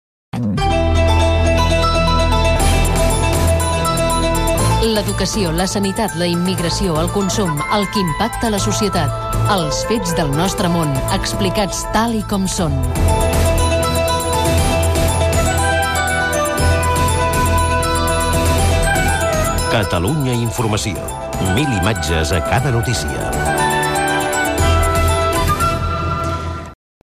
Promoció de l'emissora Gènere radiofònic Publicitat